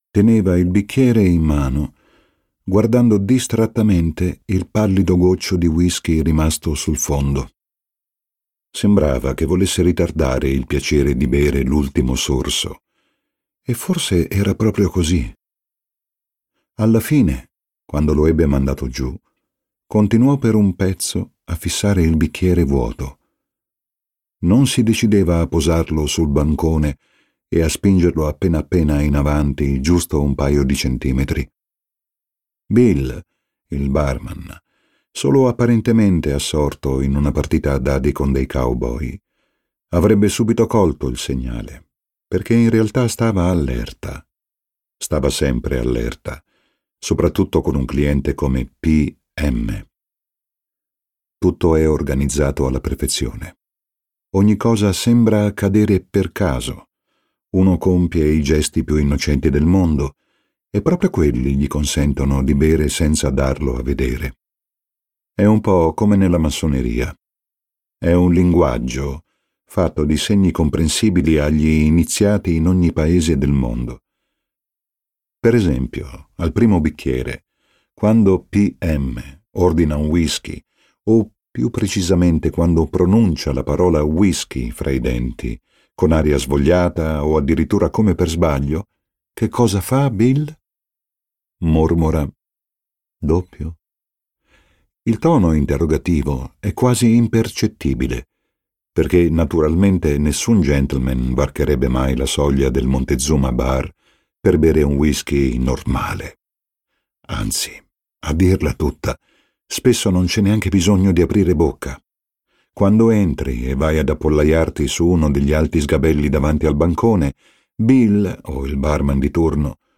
letto da Tommaso Ragno
Versione audiolibro integrale